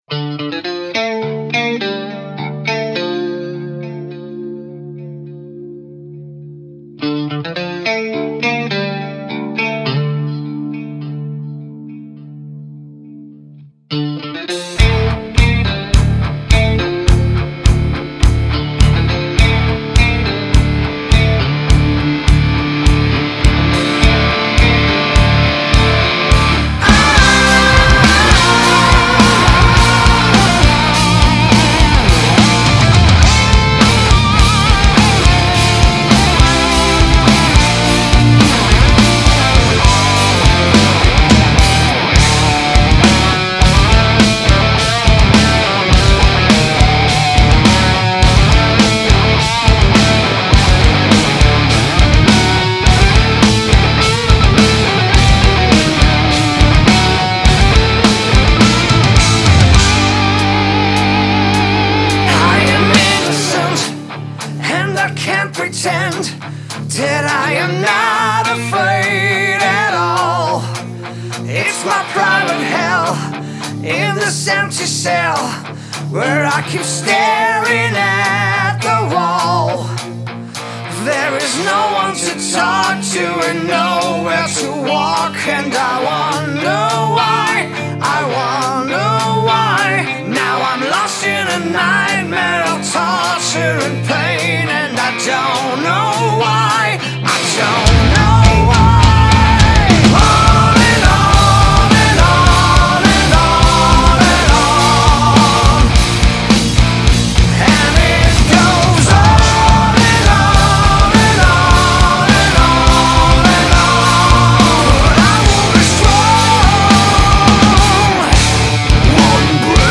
Epic Melodic Metal
sophisticated, melodic rock and metal